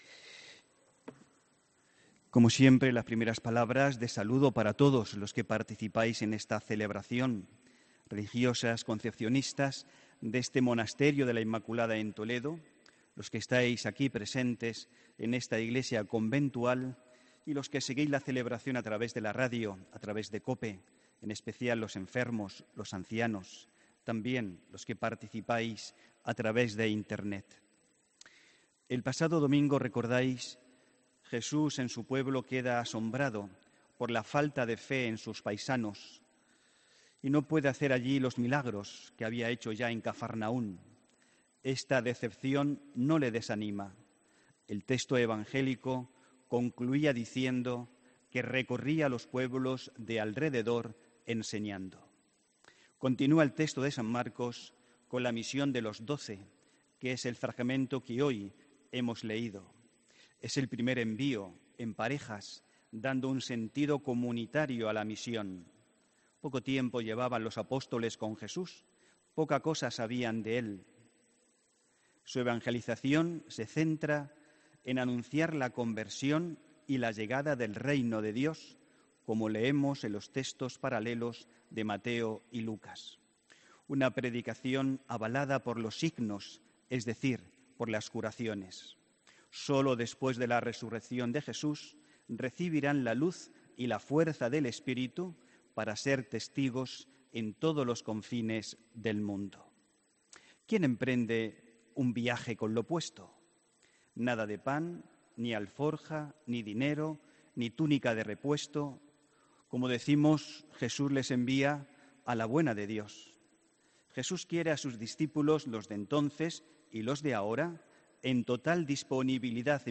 HOMILÍA 15 JULIO 2018